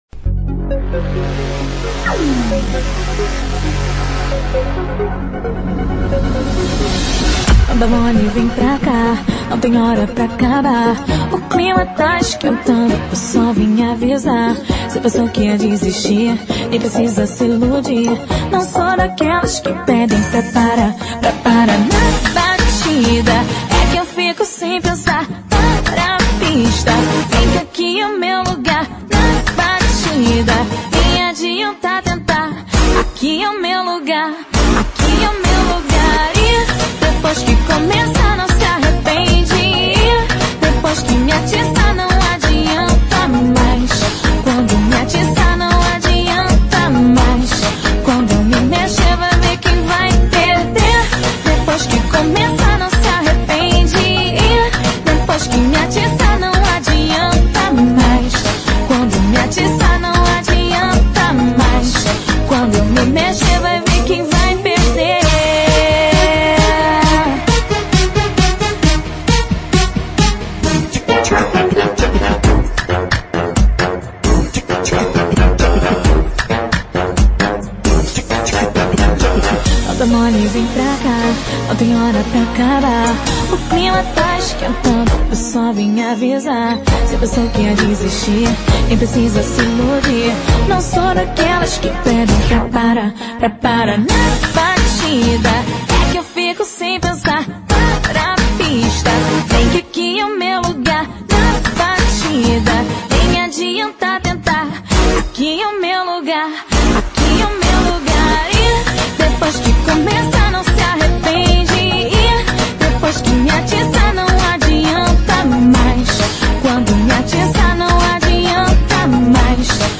Funk